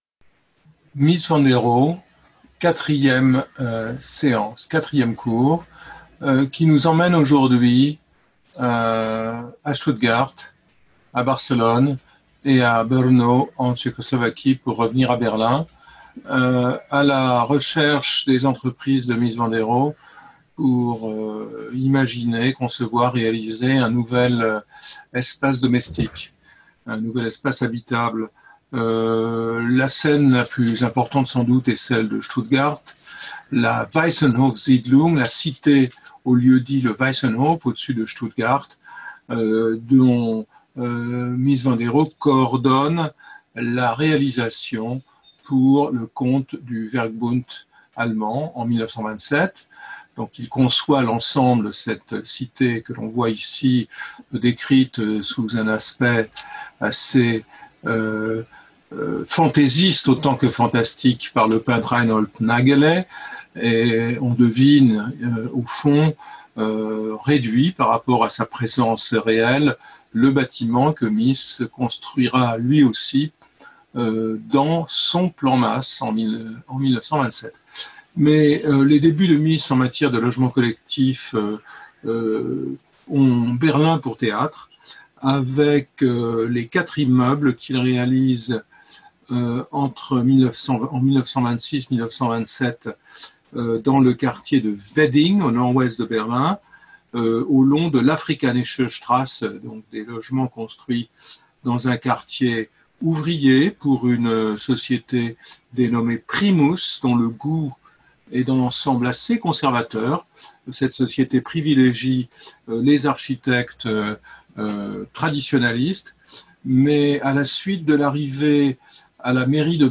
With the German Pavilion at the Barcelona Exhibition (1929) and the Tugendhat House in Brno (1930), he imagined a new space, in which activities unfold in fluid continuity, made possible by the rigorous interplay between the walls stretched in noble materials and the fine nickel-plated steel columns of the framework. Documents and media Download support pdf (16.65 MB) Speaker(s) Jean-Louis Cohen Architect, Professor at New York University and Collège de France Events Previous Lecture 6 May 2020 18:00 to 19:00 Jean-Louis Cohen Introduction ; at the school of Bruno Paul and Peter Behrens ; the Riehl h…